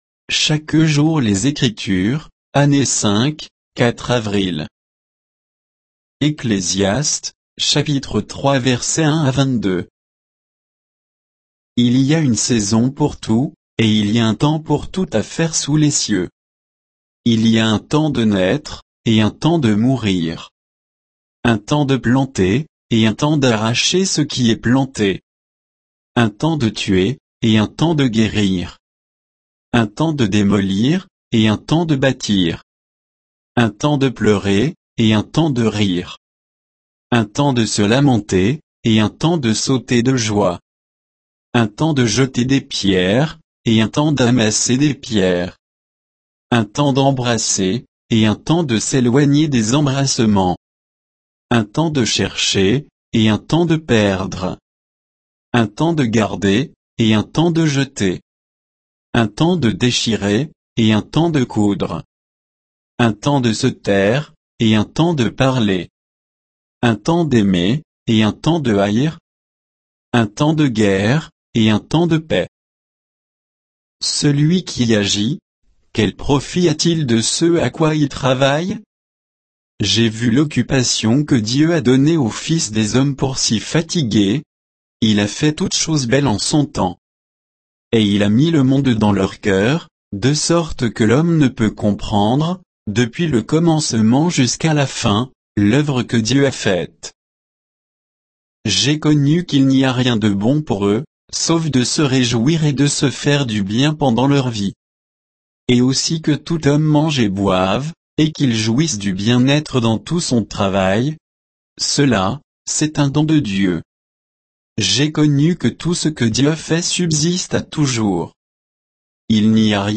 Méditation quoditienne de Chaque jour les Écritures sur Ecclésiaste 3